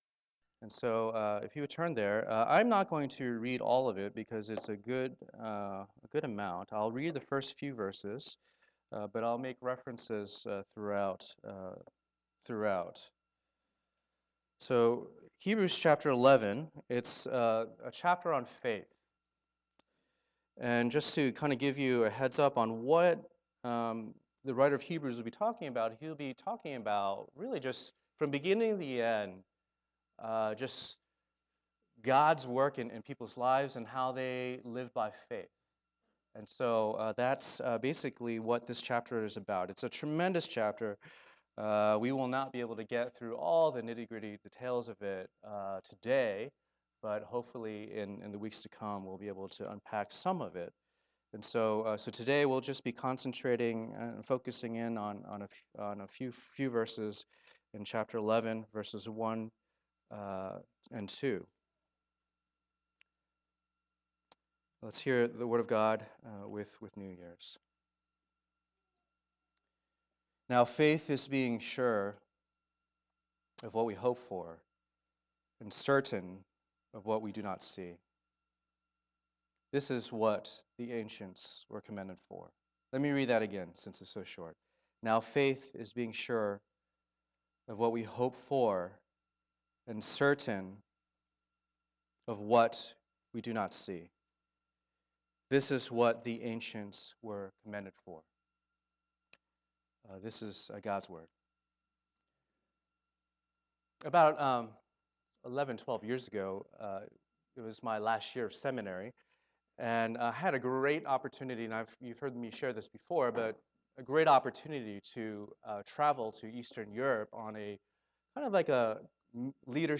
Passage: Hebrews 11:1-2 Service Type: Lord's Day